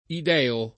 id$o] etn. stor. — del monte Ida in Creta: Zeus ideo; l’antro ideo; o del monte Ida nella Troade: Cibele idea o la madre idea — es. con acc. scr.: come l’idèo Rapitor suo primo [k1me ll id$-o rapit1r Suo pr&mo] (D’Annunzio) — sim. il pers. m. stor. Ideo